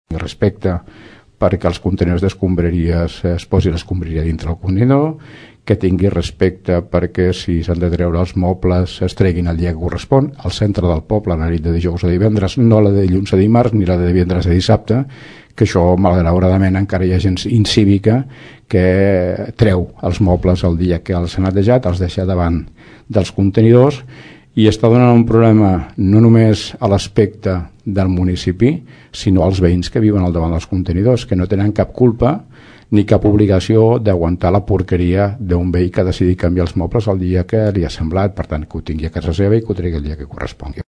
Joan Carles García, Alcalde de Tordera, explica que els agents cívics només són la mostra d’un seguit de campanyes que es duran a terme per fomentar un Tordera millor.